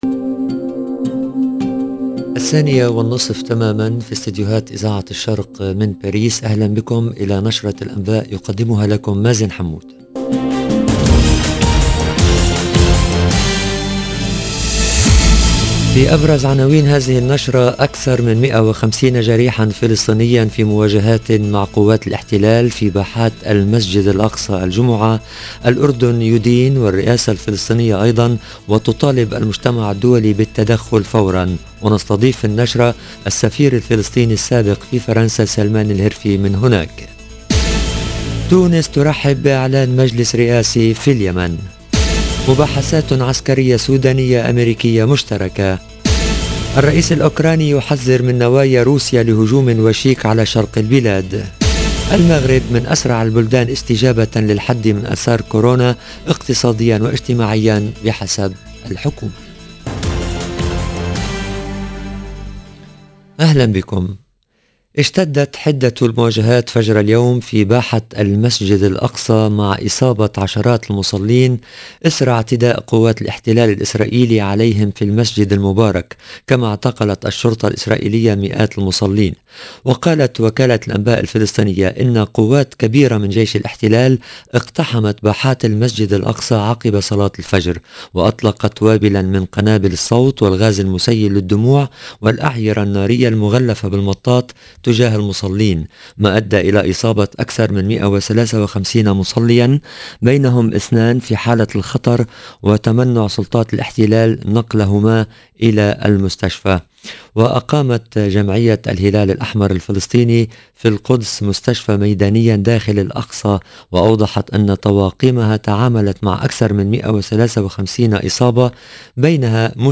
LE JOURNAL DE 14H30 EN LANGUE ARABE DU 15/4/2022
EDITION DU JOURNAL EN LANGUE ARABE DU 15/4/2022